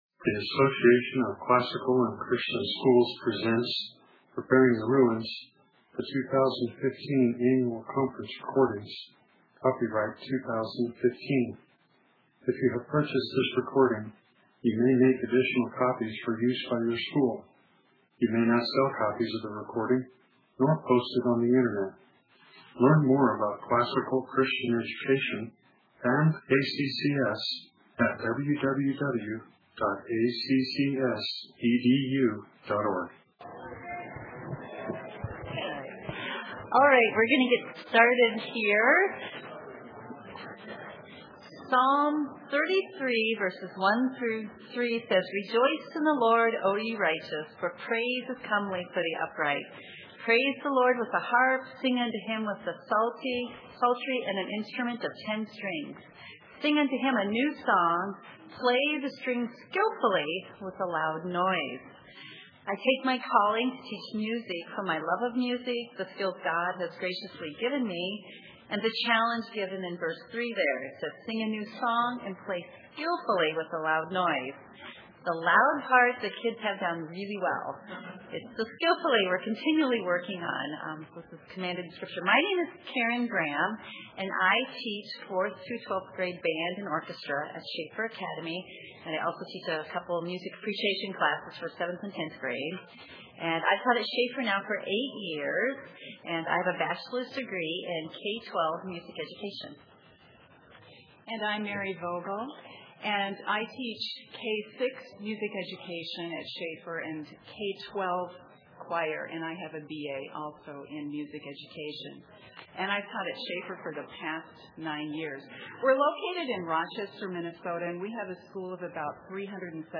2016 Workshop Talk, 1:09:39, All Grade Levels, Academics & Curriculum, Art & Music
This practical workshop is geared to the small school music specialists who wish to implement a band, orchestra, or choral program in their school. We will begin with curriculum suggestions, discuss rehearsal techniques, focus, resources, and end with tried and true music literature for concert performances at all levels. The last few minutes will be devoted to Q & A.